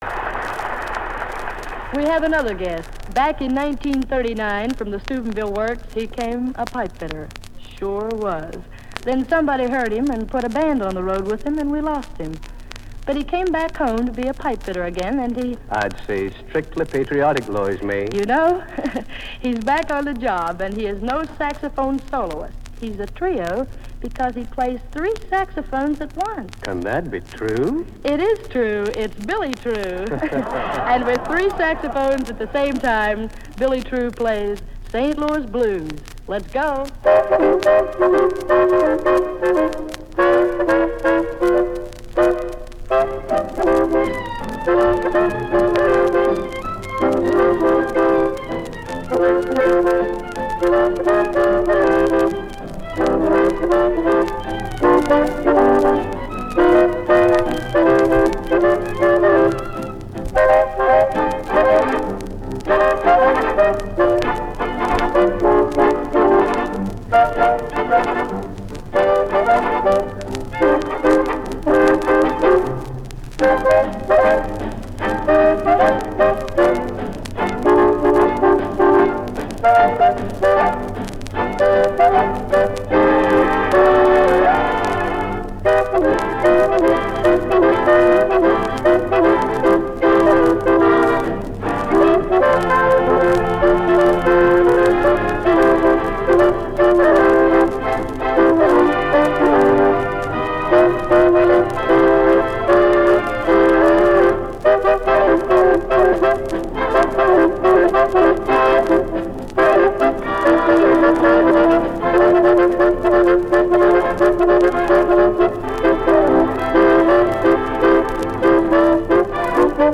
Radio Broadcasts